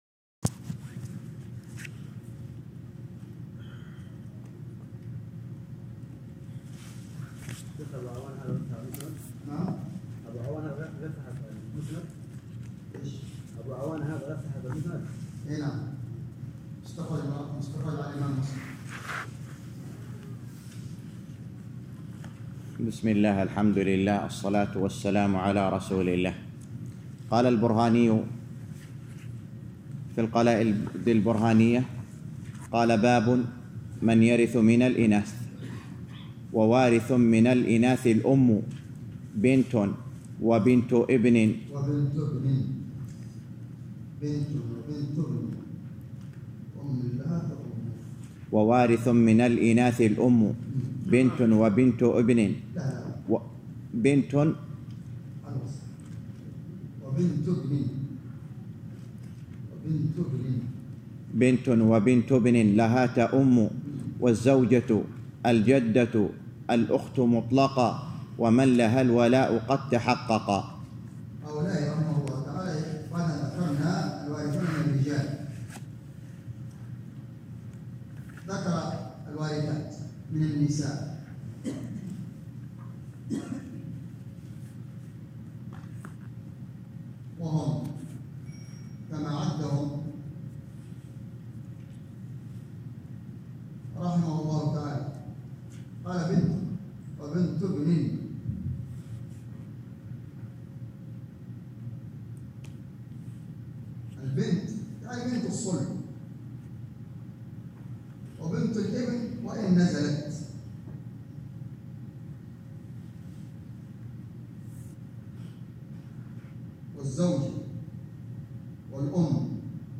الدرس السابع - شرح المنظومة البرهانية في الفرائض _ 7